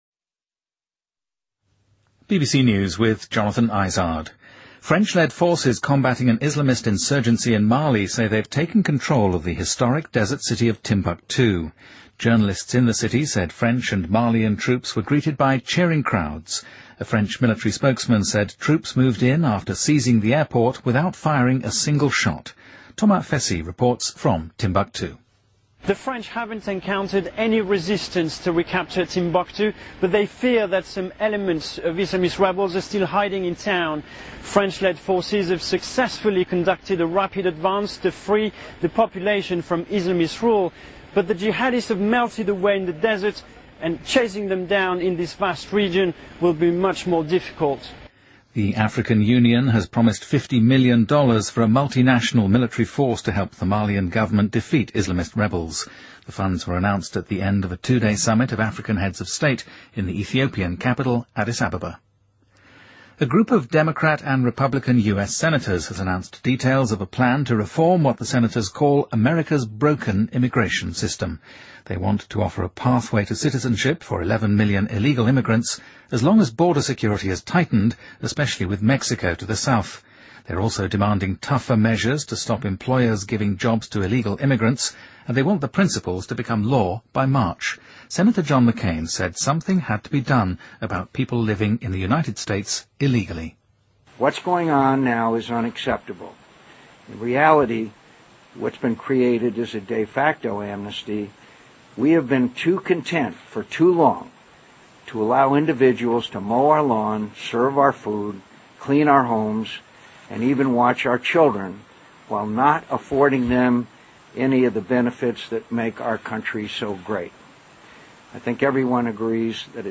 BBC news,荷兰女王贝娅特丽克丝宣布她将在四月底将王位世袭传于亚历山大王子